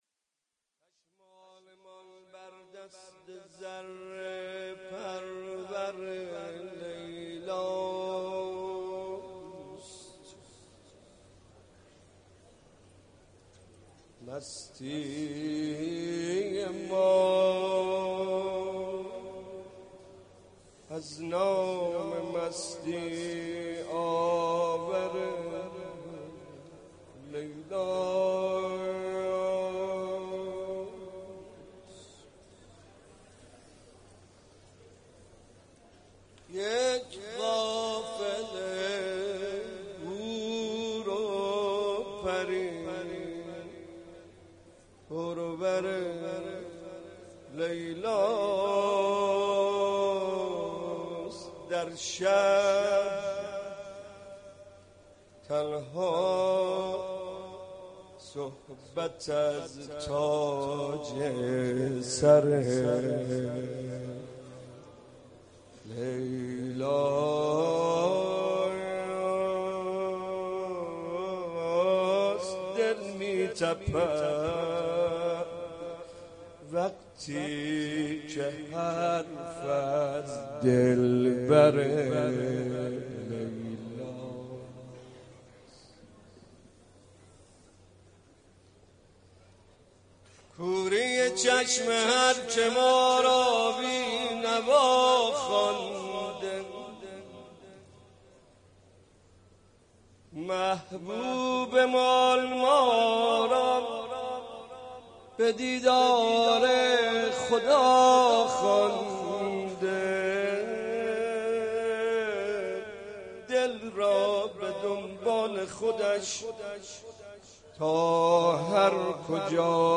مدح خوانی و توسل ویژه ولادت حضرت علی اکبر(ع)